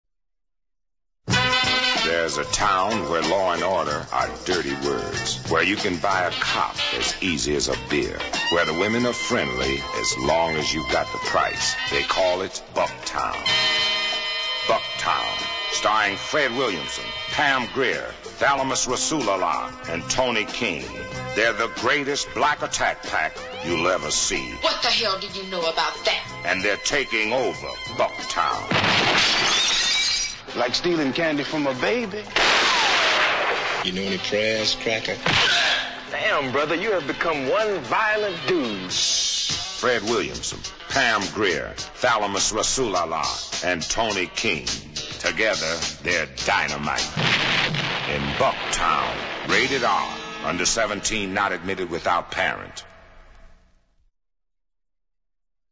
Radio spot